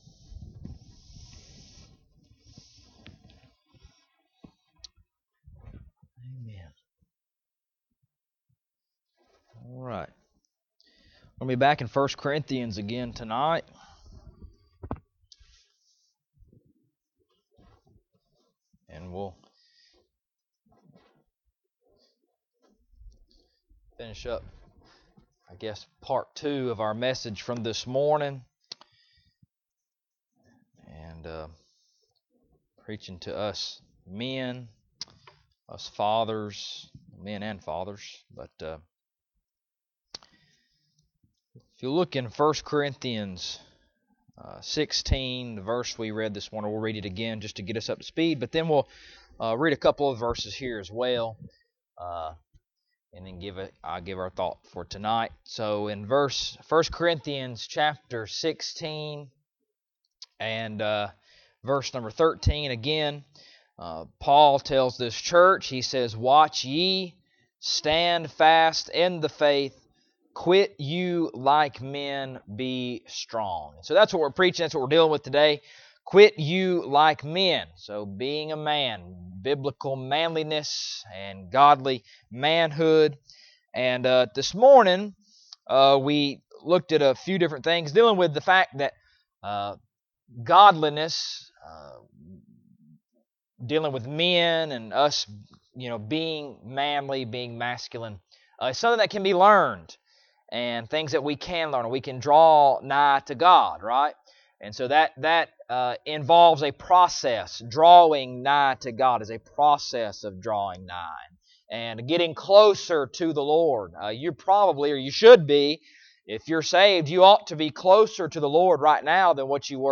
1 Corinthians Service Type: Sunday Evening Bible Text